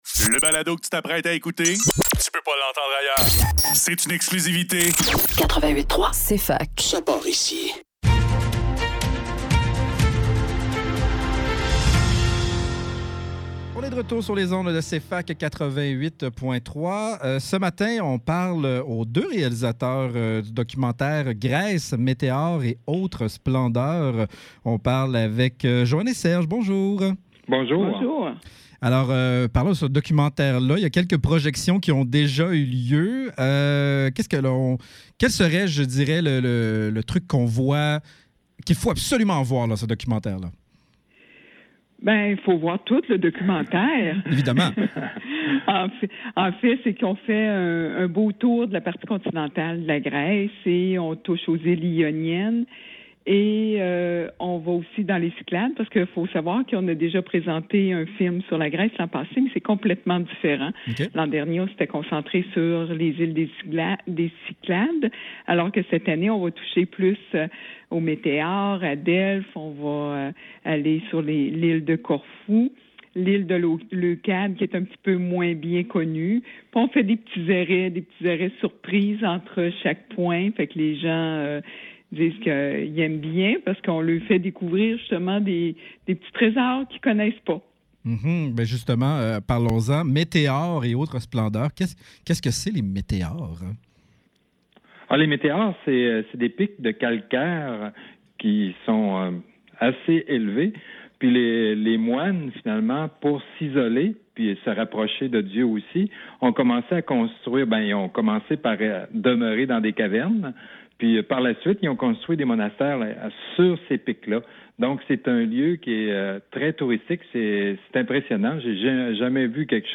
Le NEUF - Entrevue : GRÈCE, Météores et autres splendeurs - 13 novembre 2024